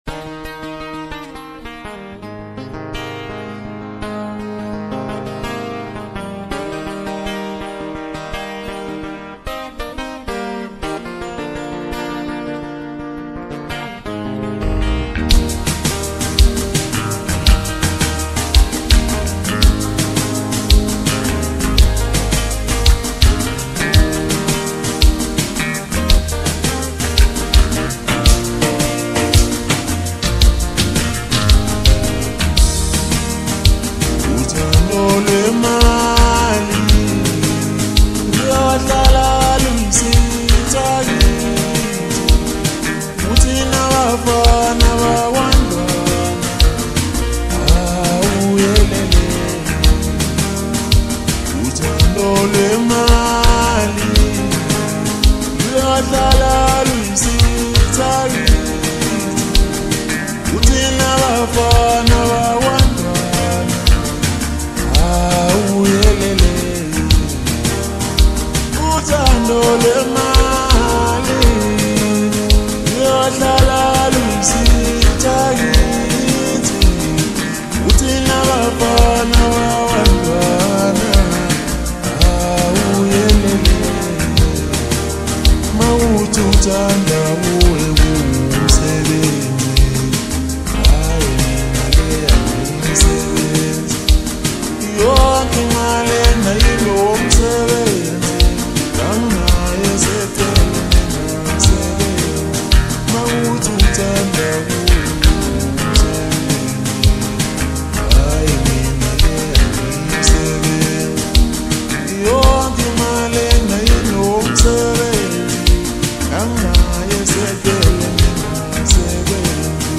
Maskandi